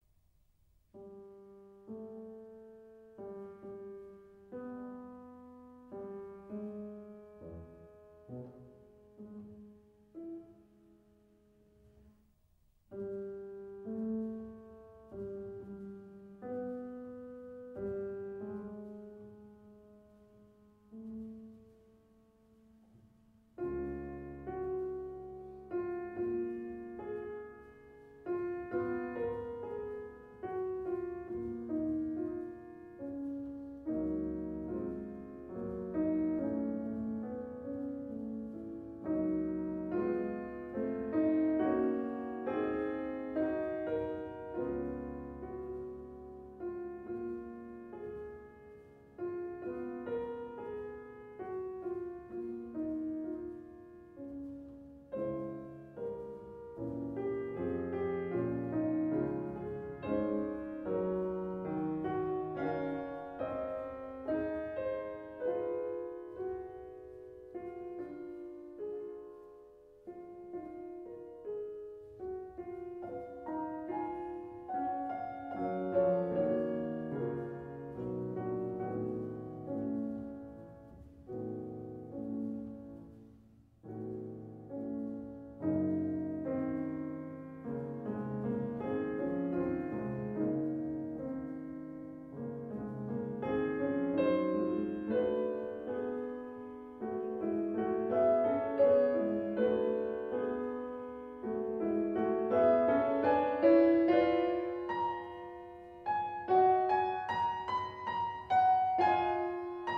String Quartet in G minor
Andantino, doucement expressif